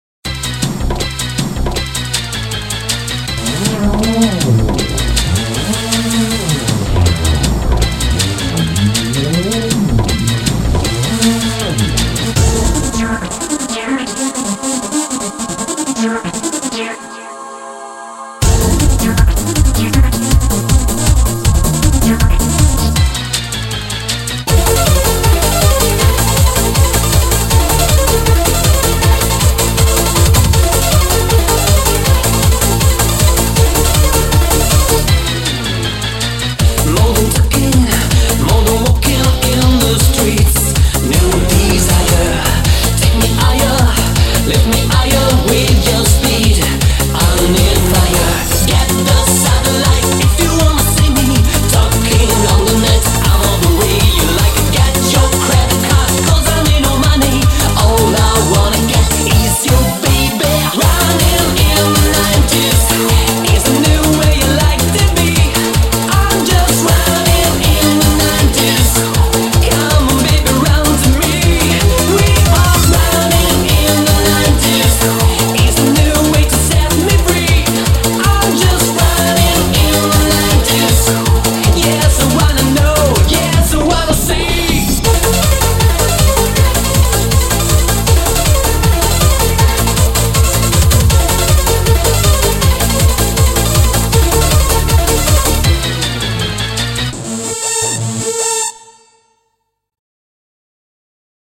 BPM158